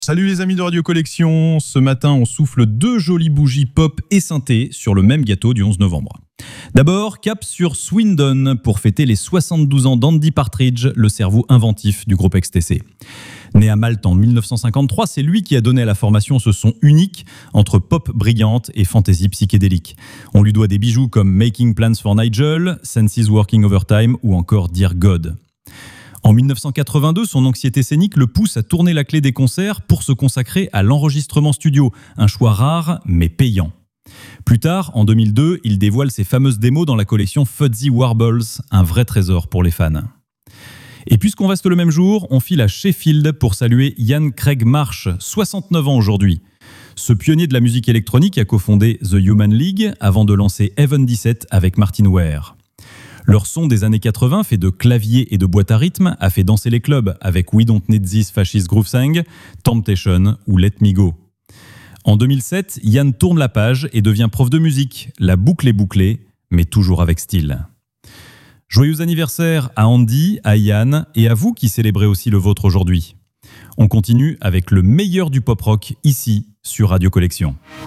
Une chronique vivante qui mêle souvenirs, anecdotes et découvertes pour un véritable voyage quotidien dans l’histoire des artistes préférés des fans de Pop Rock, des années 70 à aujourd’hui.